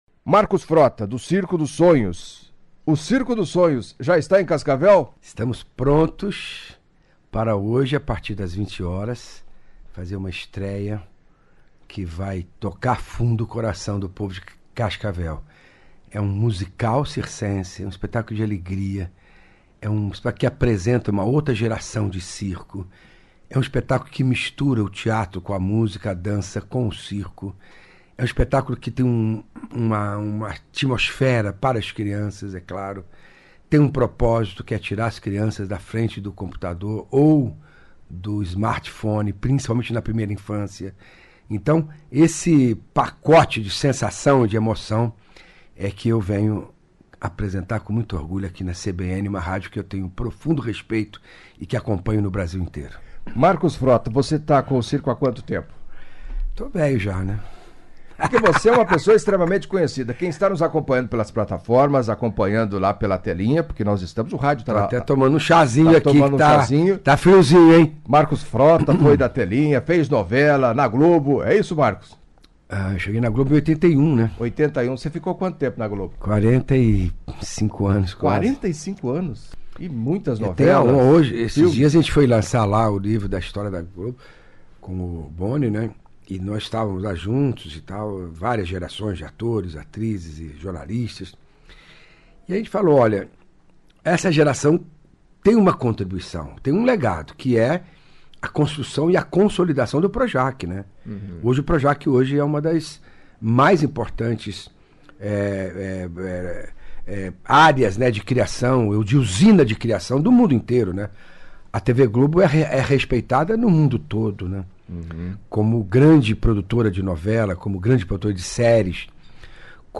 Em entrevista à CBN Cascavel nesta sexta-feira (23) o ator Marcos Frota, emocionou ouvintes ao falar da sua trajetória na TV Globo e da carreira no circo.